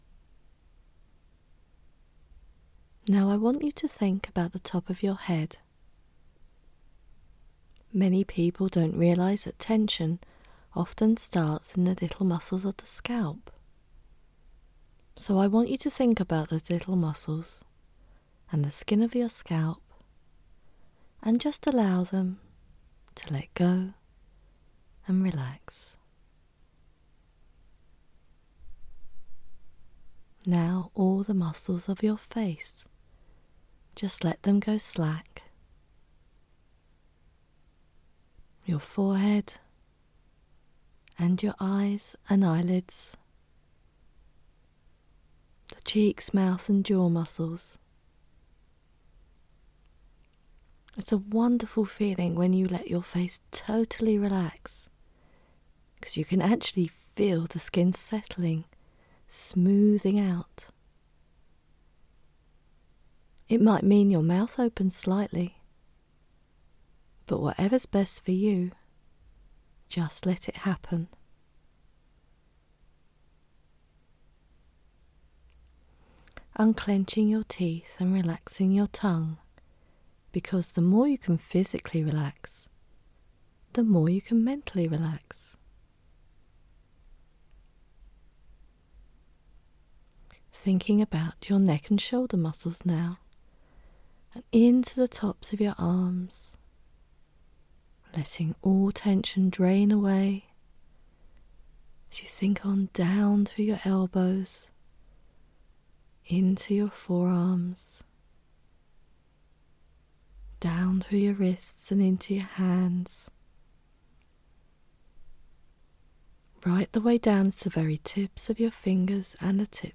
MP3 Relaxation to sleep well If you have a PC or a laptop, please download the MP3 by right-clicking here.
Relaxation without background music
Relaxation_without_music.mp3